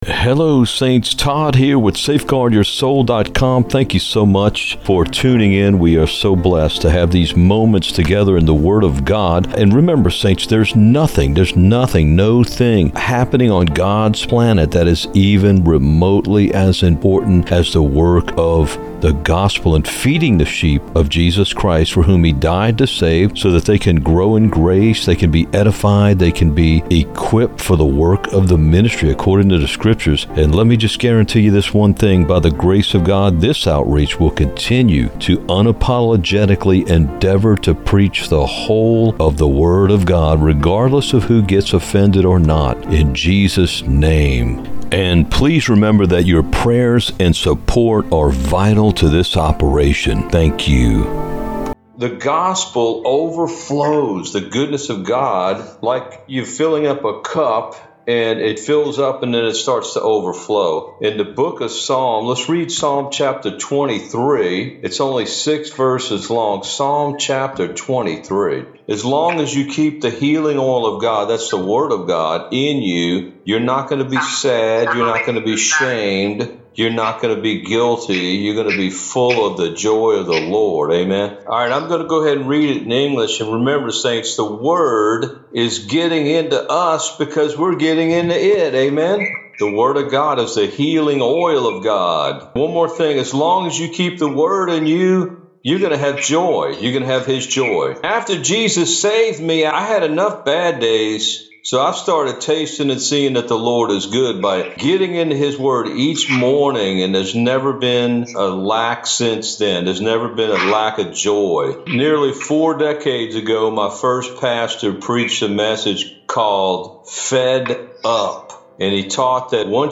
This is the English portion of a recent message that was preached to a bilingual group.
Choosing-God-EDITED-ECHO-MUSIC.mp3